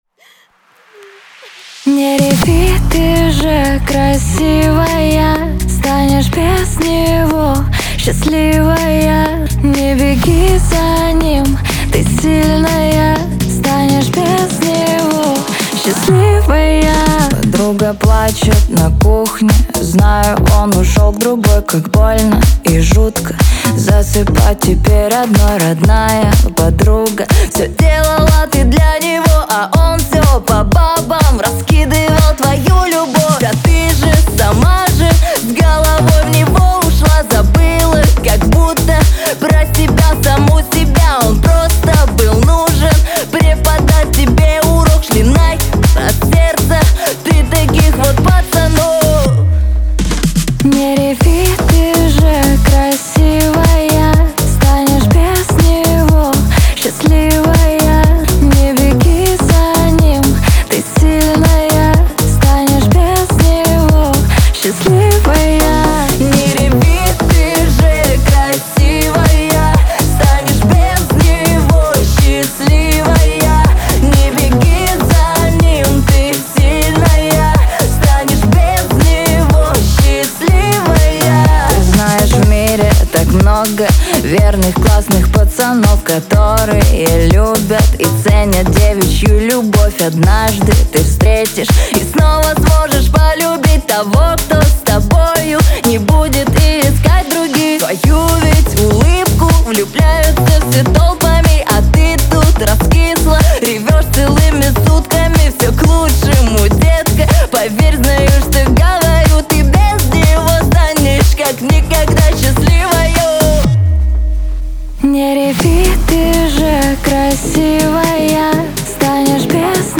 зажигательная поп-песня